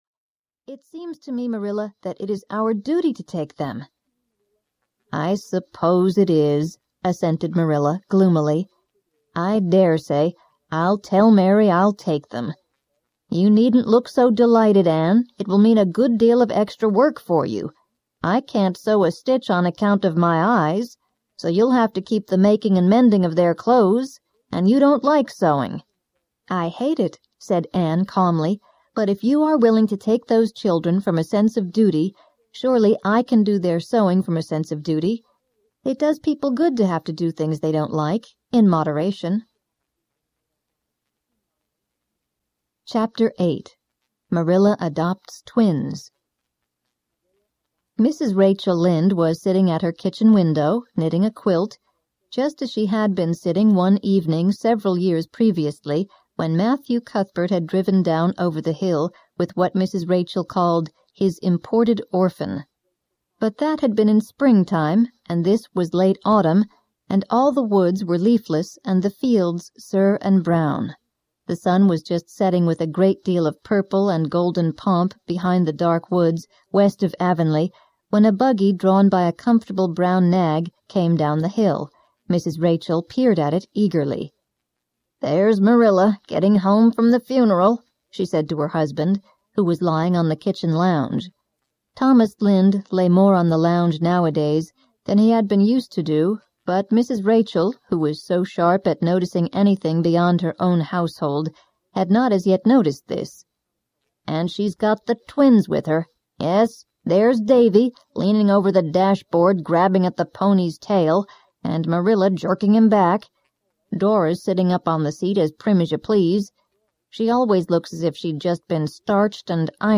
Anne of Avonlea Audiobook
10 Hrs. – Unabridged